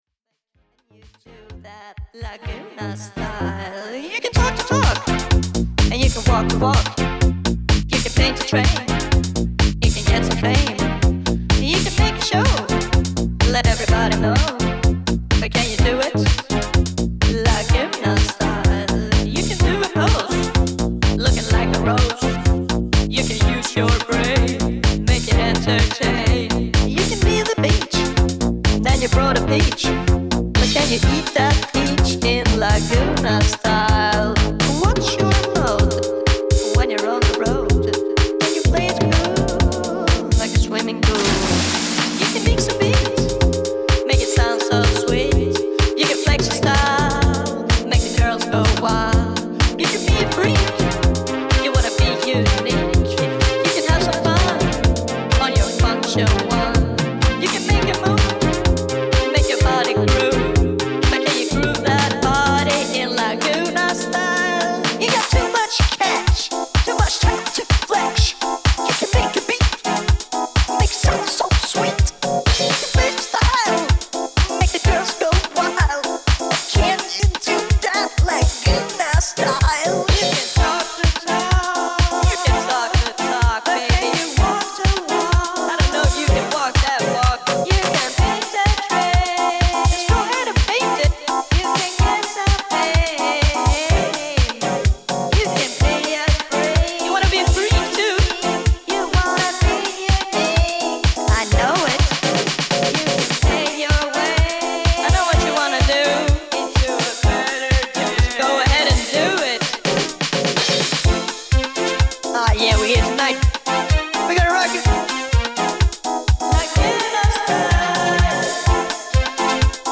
Quirky house tracks
Swedish duo